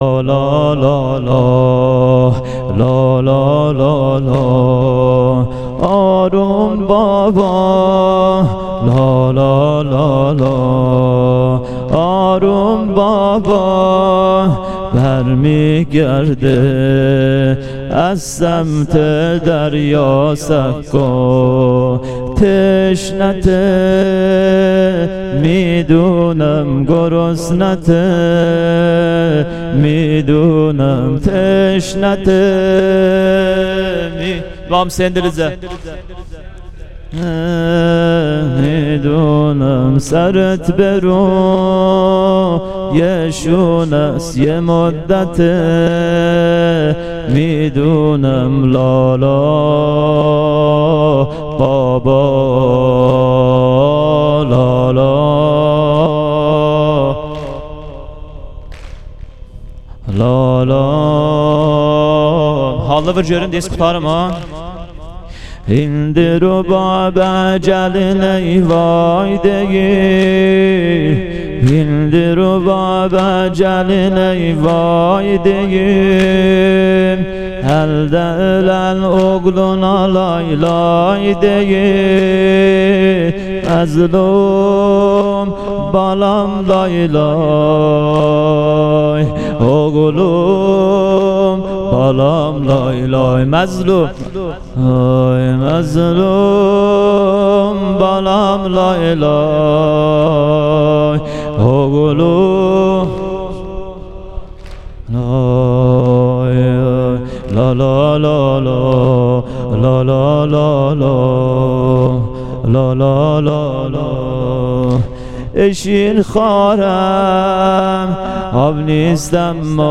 شب هفتم محرم 98 - بخش چهارم سینه زنی(تک)